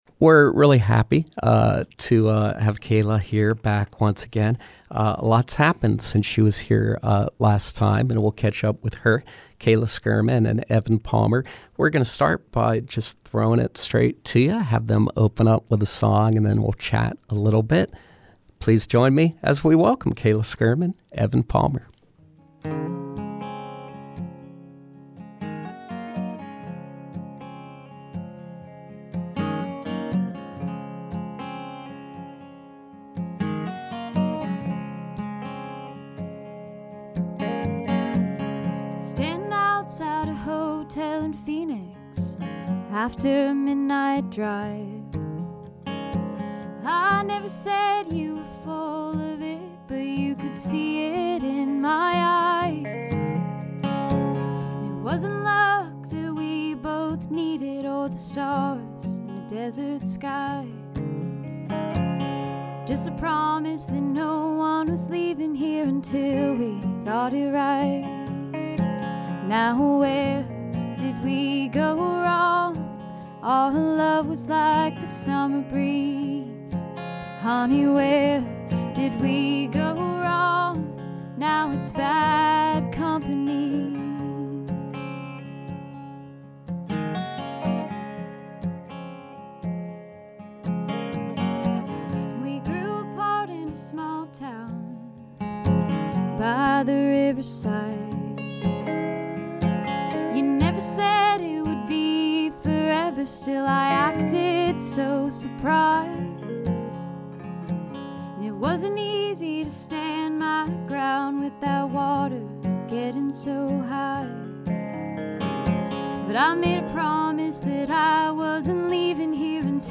singer-songwriter
guitarist